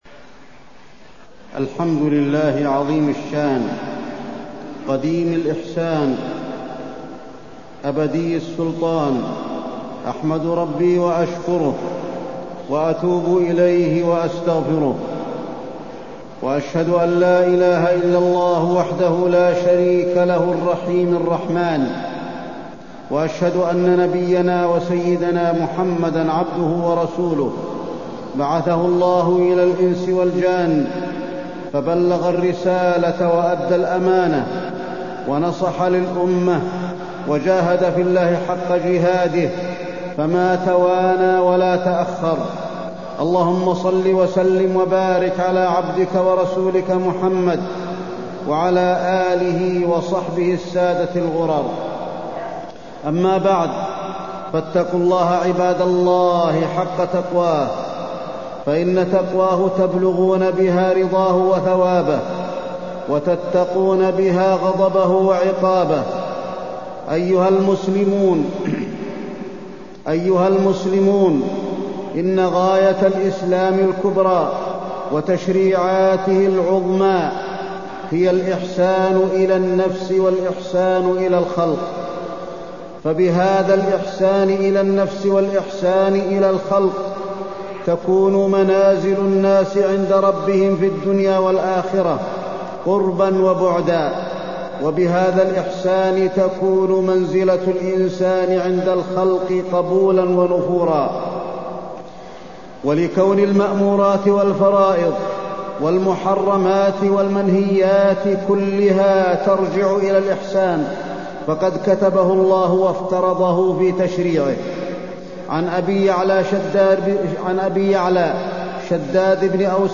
تاريخ النشر ٣ شعبان ١٤٢٤ هـ المكان: المسجد النبوي الشيخ: فضيلة الشيخ د. علي بن عبدالرحمن الحذيفي فضيلة الشيخ د. علي بن عبدالرحمن الحذيفي الإحسان